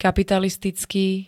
kapitalistický [-t-] -ká -ké príd.